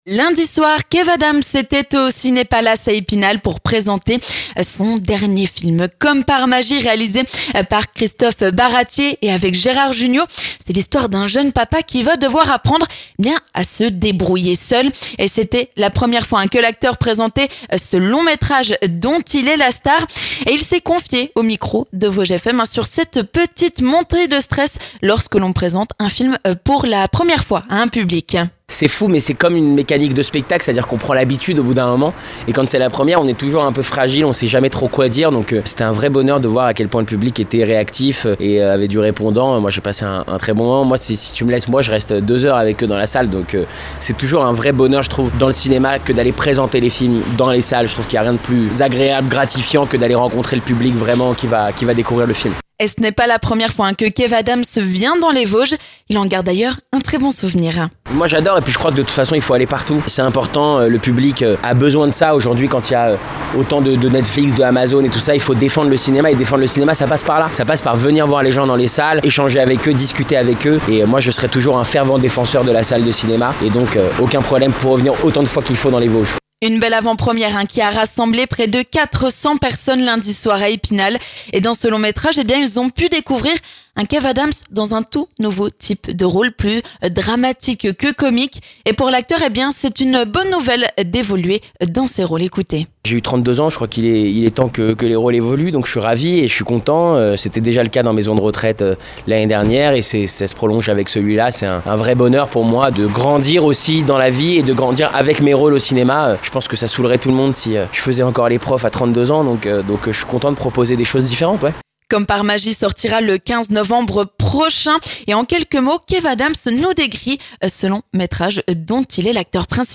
A la sortie de sa rencontre avec le public, Kev Adams a accepté de répondre à nos questions !